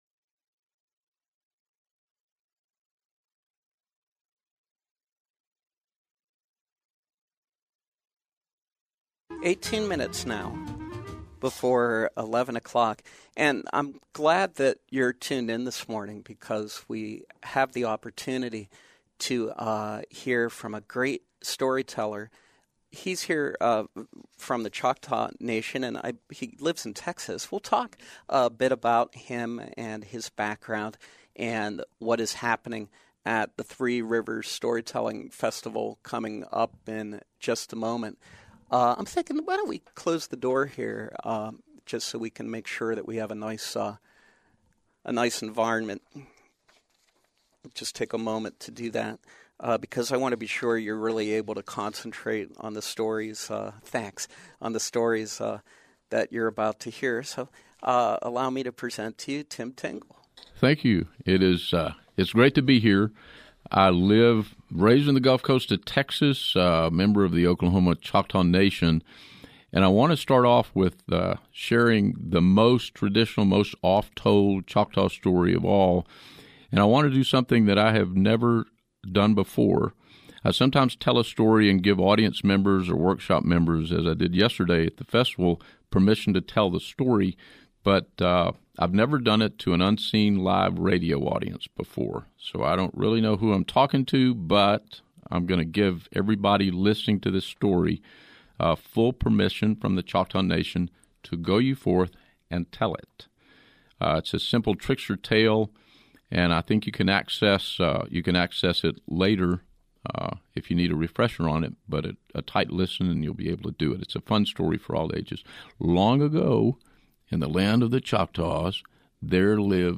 Storyteller Tim Tingle